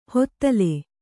♪ hottale